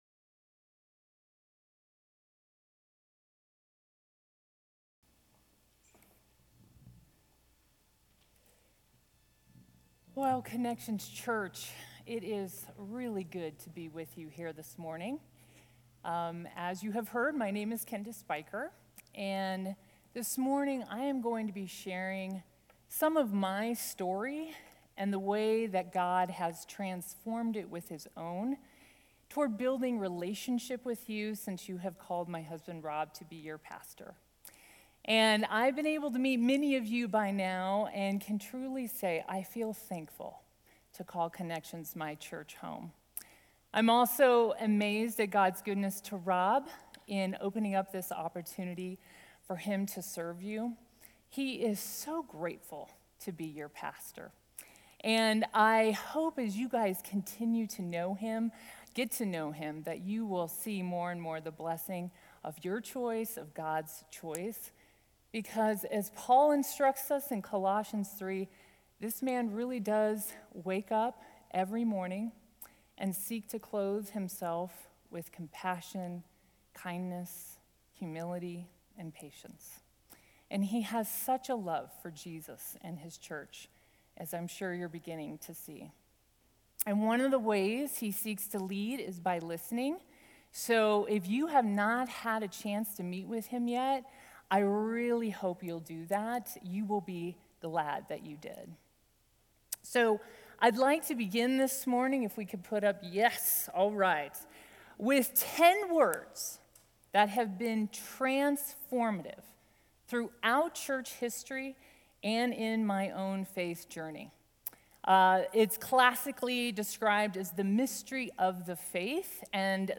From Series: "Guest Speaker"